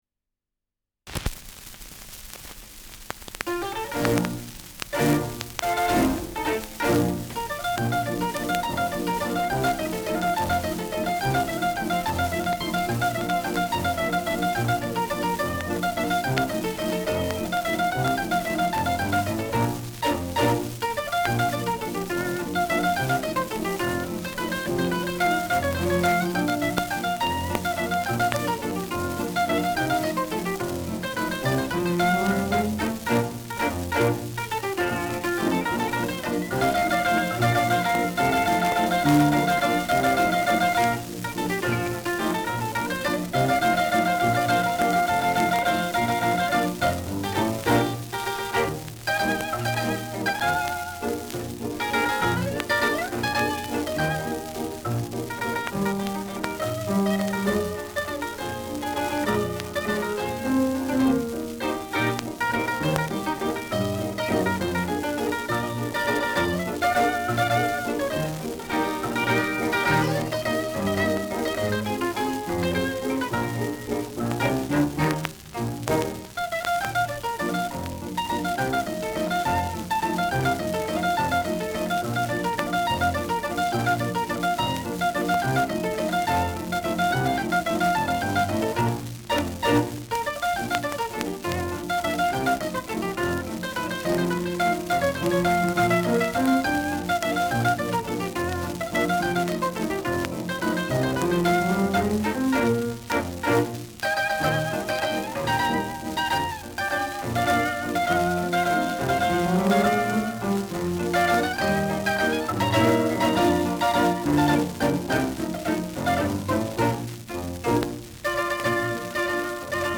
Schellackplatte
Leicht abgespielt : Erhöhtes Grundrauschen : Häufiges Knacken
[unbekanntes Ensemble] (Interpretation)
[Berlin] (Aufnahmeort)